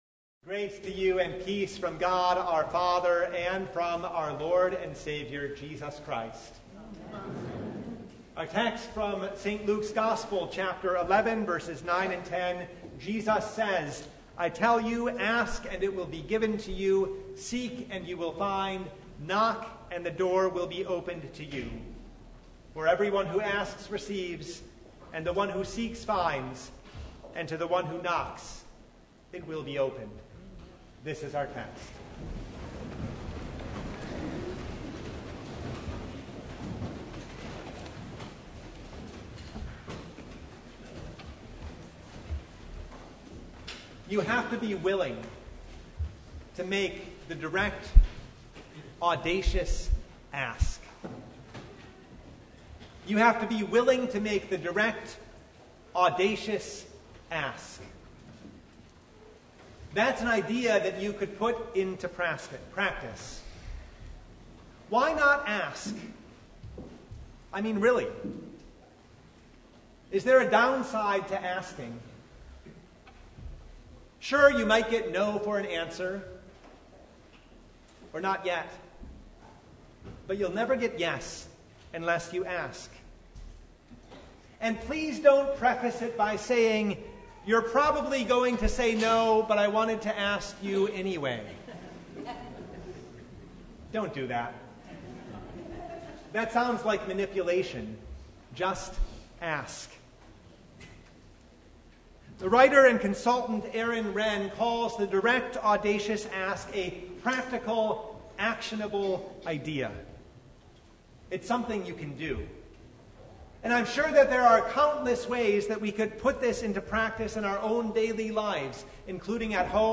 Service Type: Sunday
Sermon Only